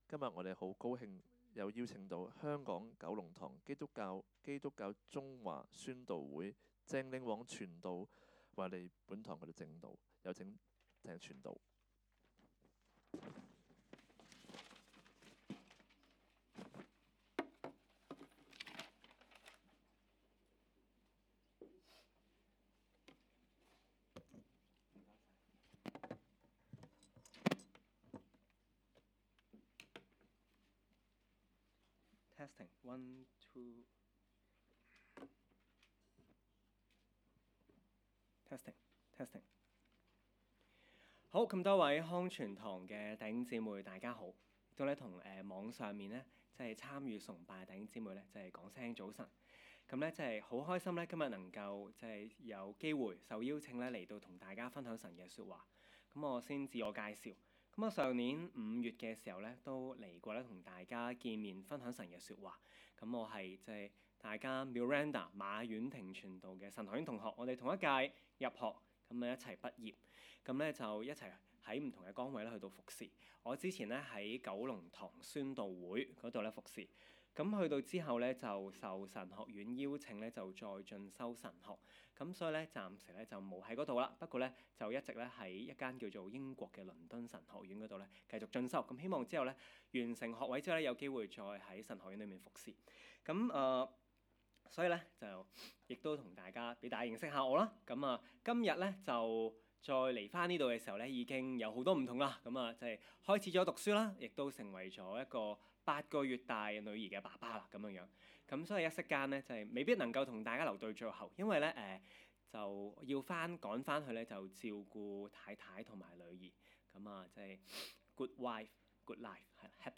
講道 ：希望祢呈羊性